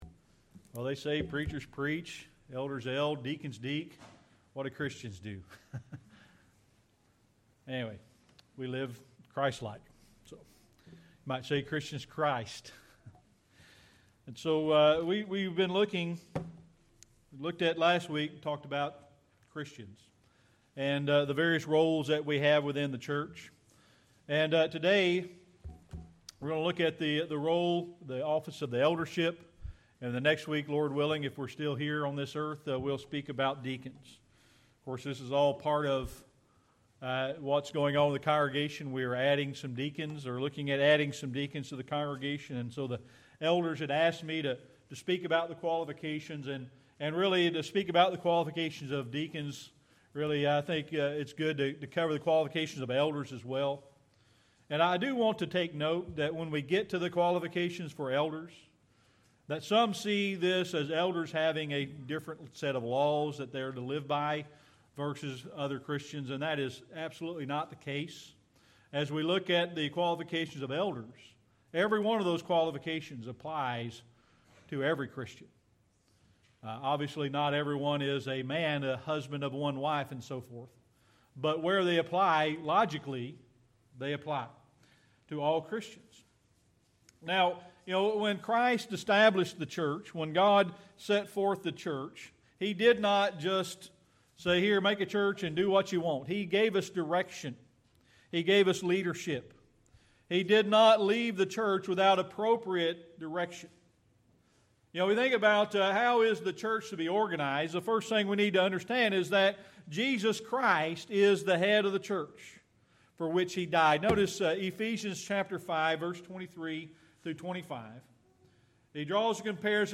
1 Timothy 3:1-3 Service Type: Sunday Morning Worship You've probably heard it said...